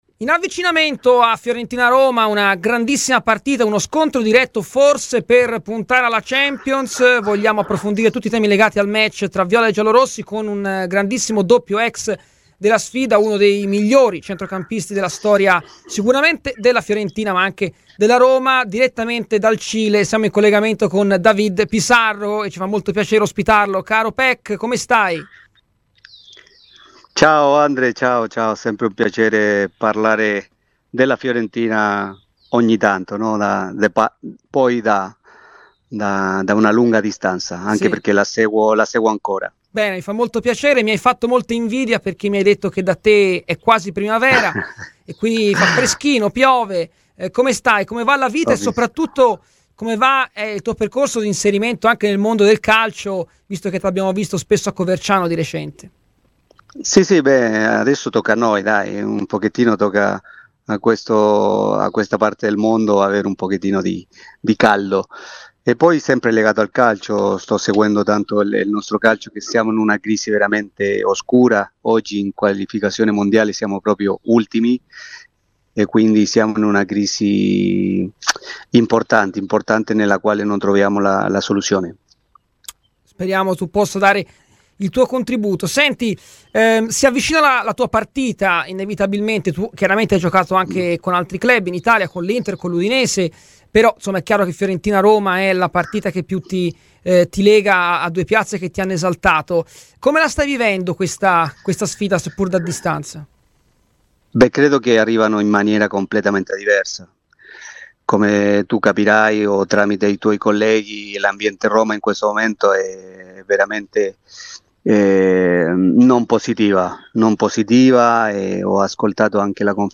Ecco le sue parole rilasciate a Radio FirenzeViola : Pizarro, si avvicina la "sua" partita: come sta vivendo la marcia d'avvicinamento?